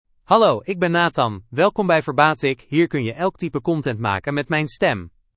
Nathan — Male Dutch AI voice
Nathan is a male AI voice for Dutch (Netherlands).
Voice sample
Male
Nathan delivers clear pronunciation with authentic Netherlands Dutch intonation, making your content sound professionally produced.